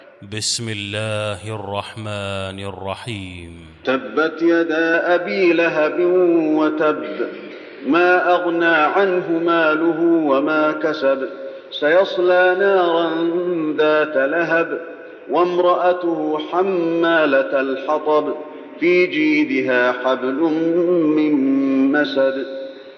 المكان: المسجد النبوي المسد